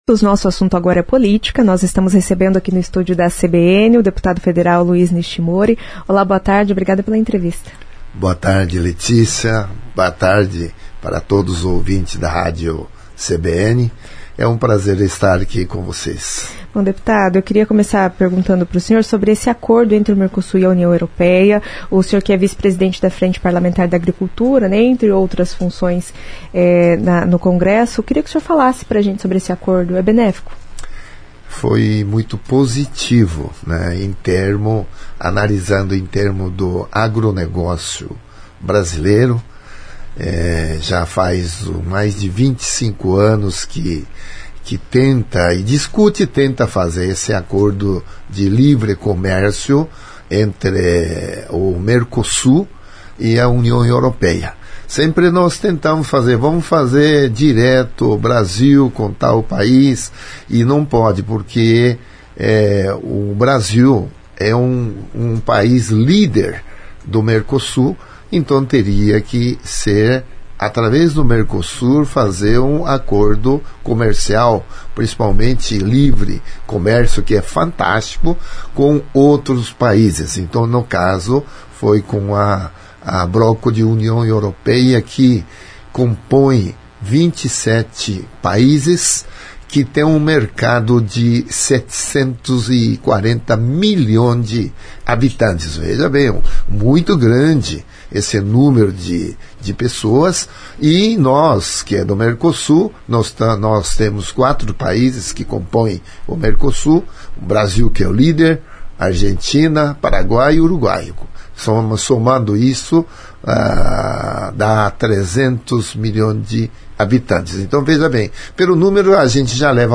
O deputado Luiz Nishimori falou sobre o projeto que dá a Maringá o título de Capital Nacional do Associativismo.